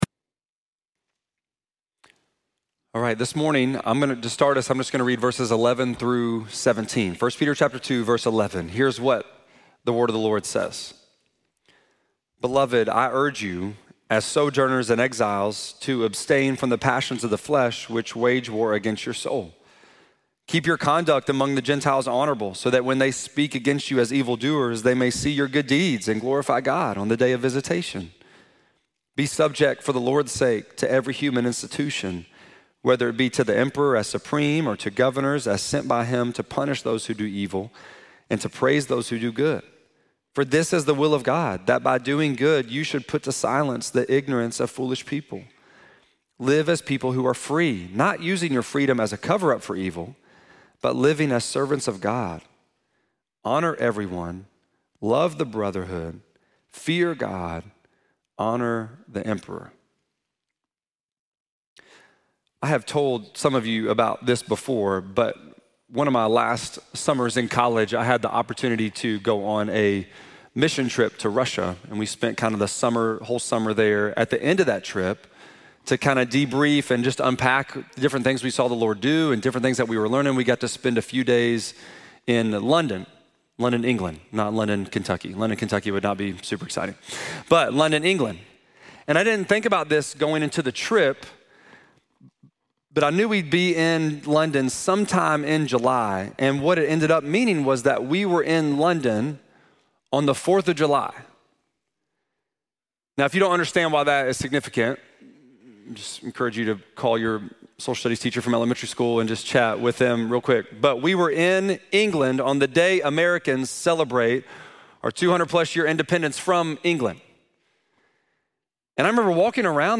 9.28-sermon.mp3